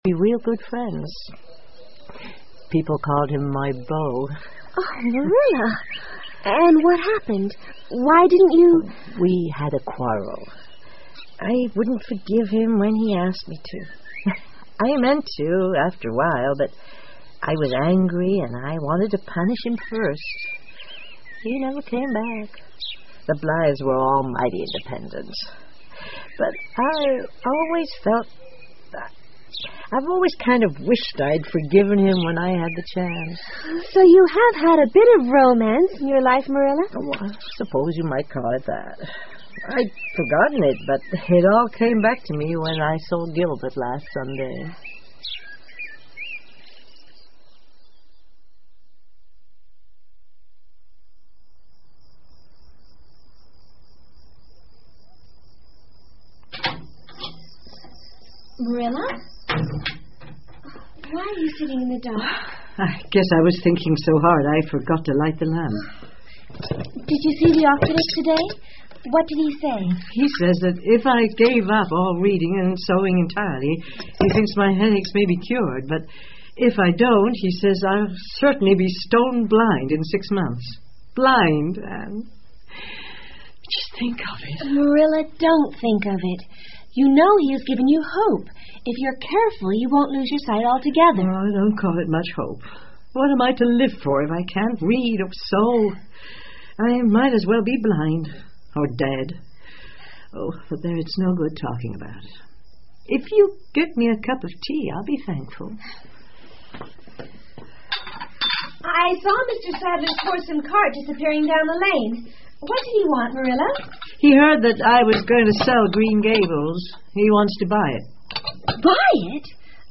绿山墙的安妮 Anne of Green Gables 儿童广播剧 28 听力文件下载—在线英语听力室